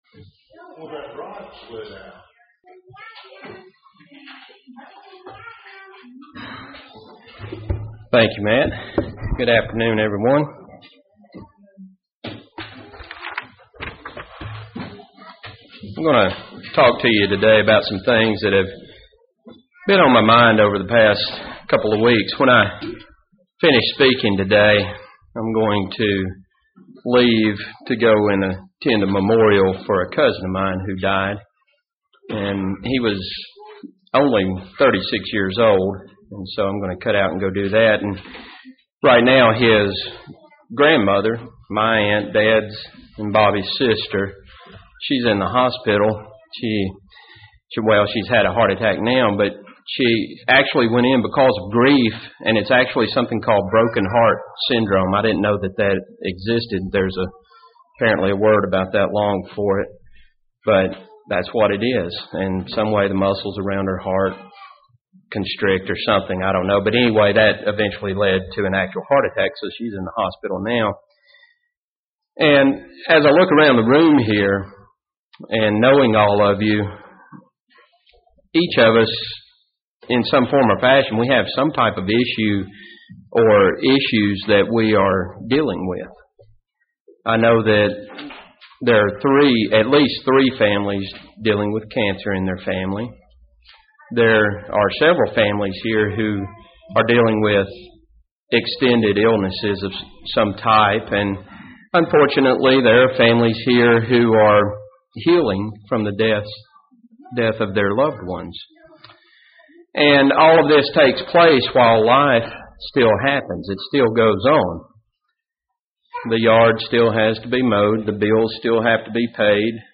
Life can be difficult at times and it is easy enough to become distracted. This sermon focuses on three points to help us deal with some of the Issues of Life.